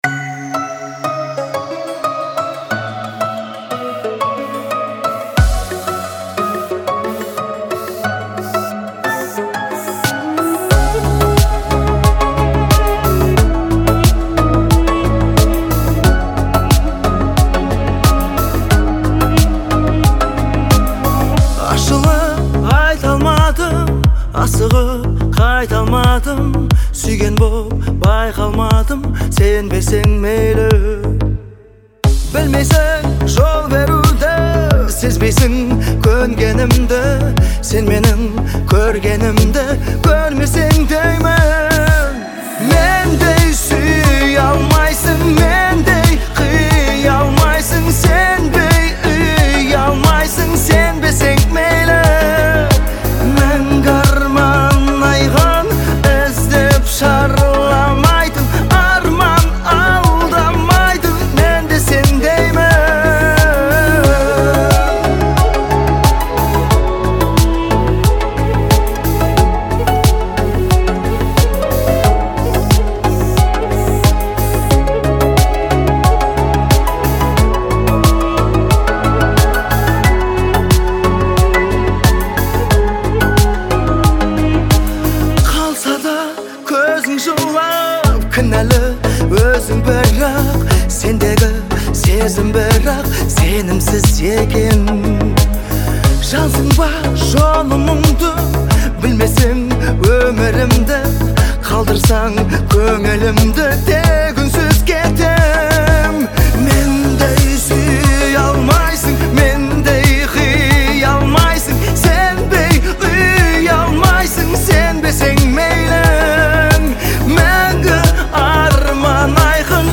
это эмоциональная песня в жанре казахского попа